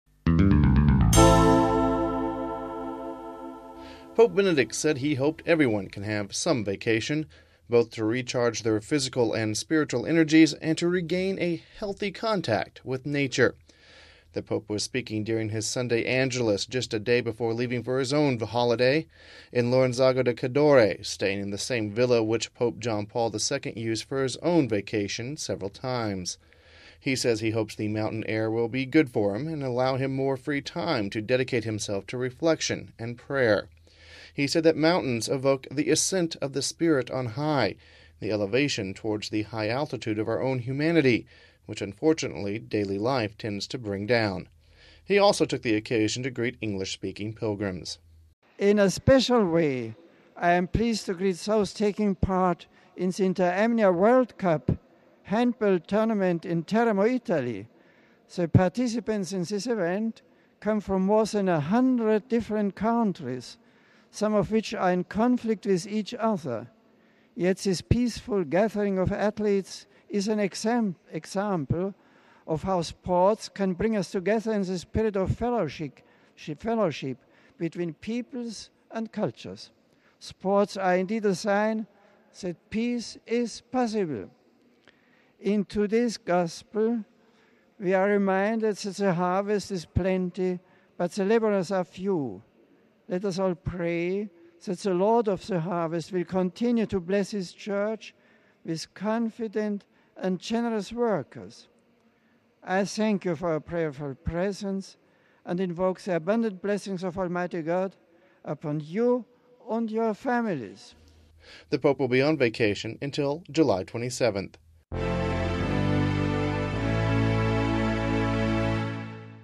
(8 July 07 - RV) Pope Benedict XVI says he hopes everyone can have a good vacation. He was speaking before leaving on his vacation to the mountains of Italy.